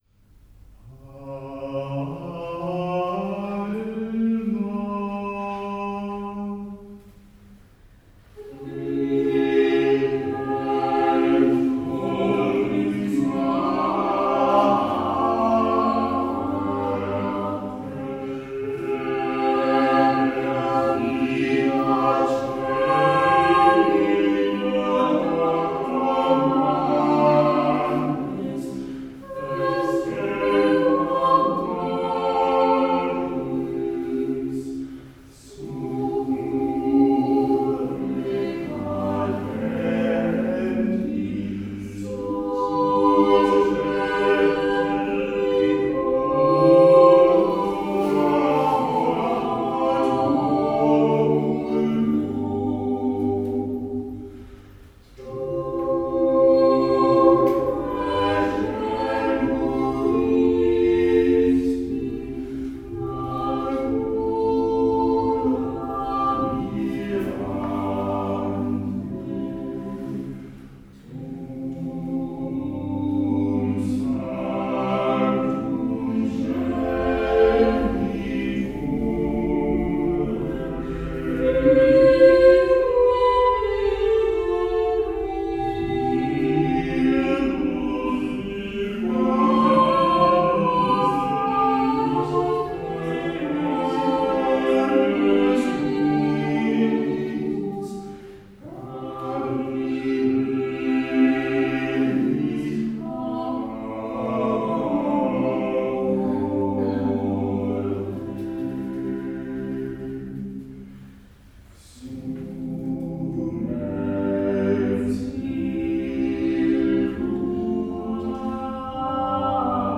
ensemble impuls – Vocal Art
Über die Jahre gab es einige Wechsel; unser Ziel ist dasselbe geblieben: Wir möchten unserem Publikum als Vokalquintett musikalische und geistige Impulse bieten.
Dazu flanieren wir durch viele Genres und Stile der a-cappella-Literatur – und bereichern unsere Konzerte gern um andere Kunstformen wie Fotografie oder Lyrik.